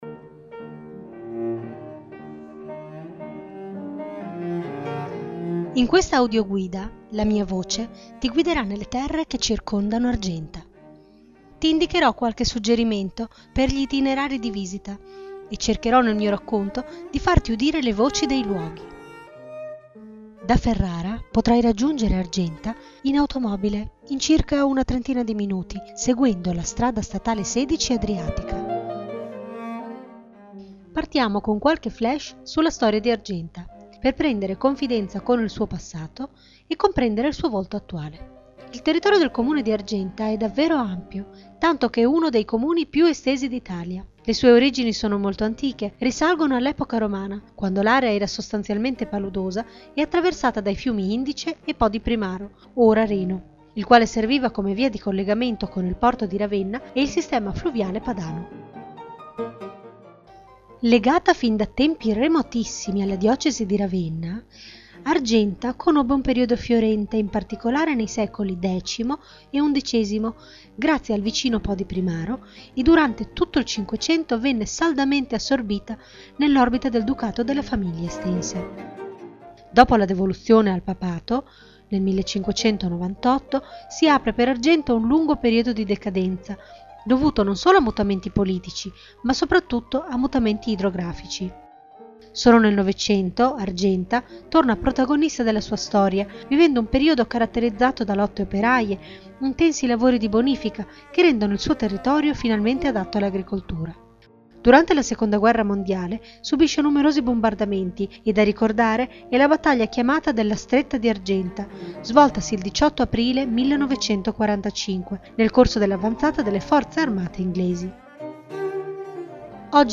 Audioguide di Argenta